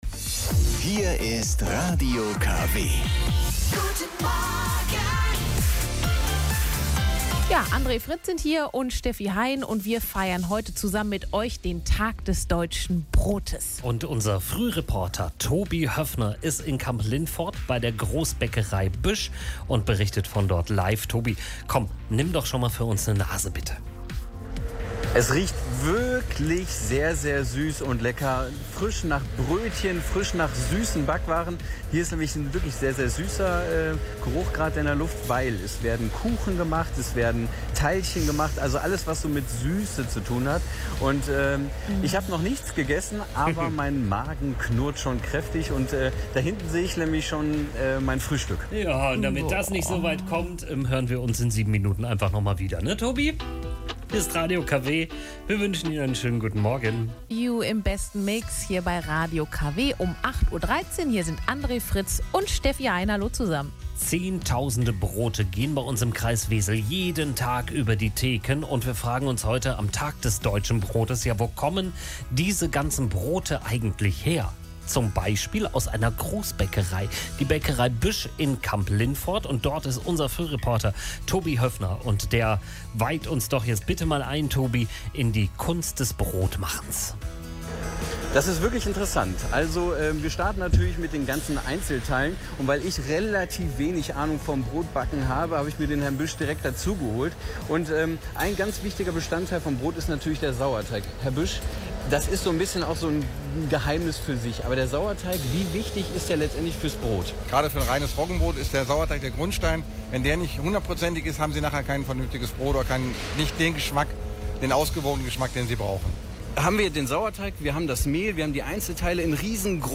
Seinen Radio-Beitrag von Radio KW können Sie hier noch Einmal hören.!